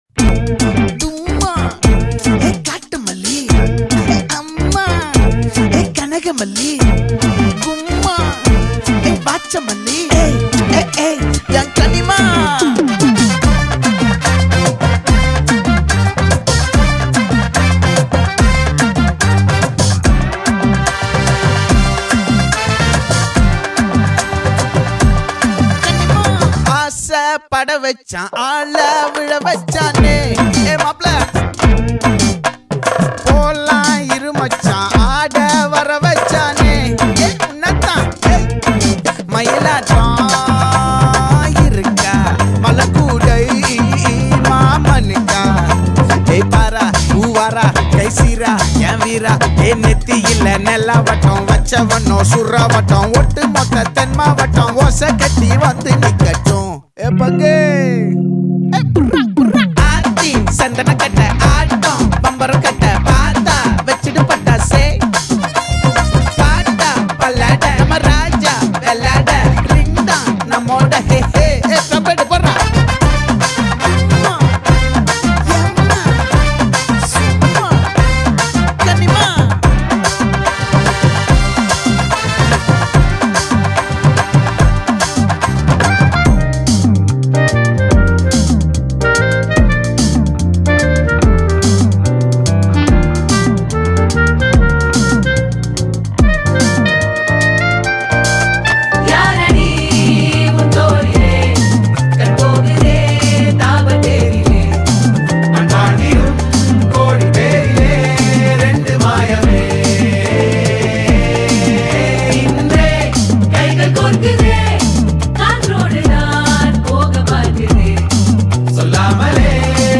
Tamil Gana